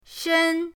shen1.mp3